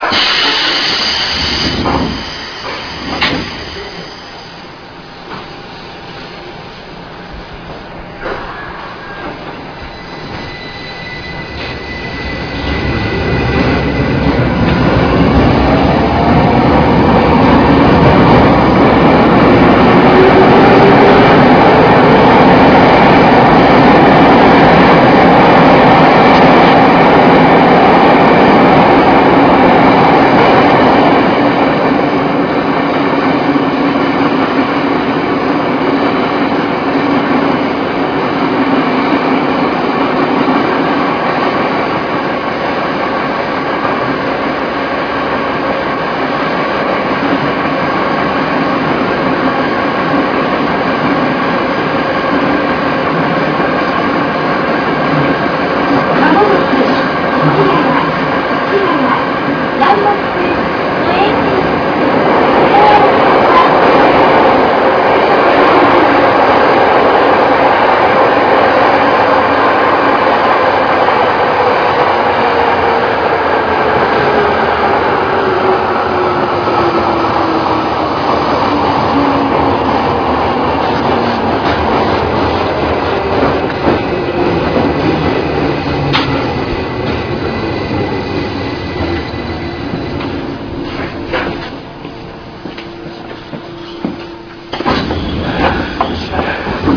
三菱製VVVFの編成は、6000系とはちょっと異なった音を出しますが、日立製VVVFの編成は他の 路線とまったく同じ音です。なかなか収録するのに苦労するタイプですね。